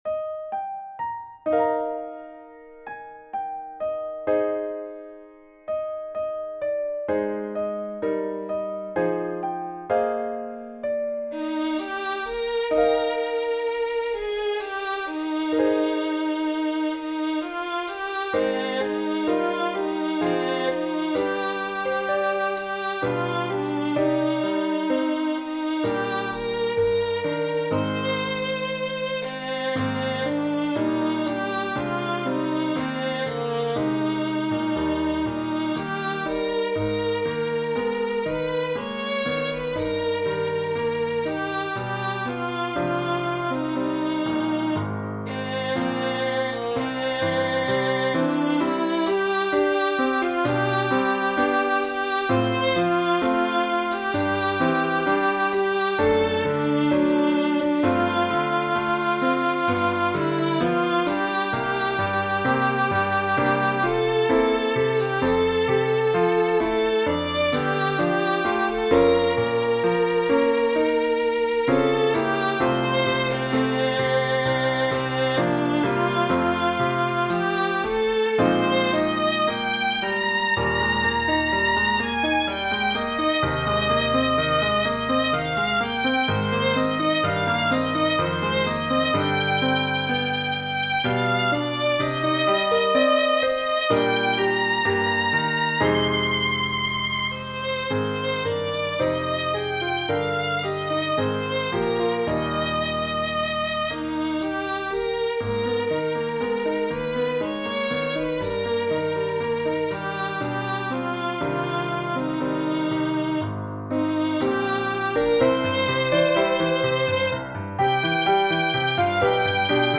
Violin Solo with Piano Accompaniment.
Voicing/Instrumentation: Violin Solo